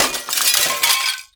GLASS_Window_Break_08_mono.wav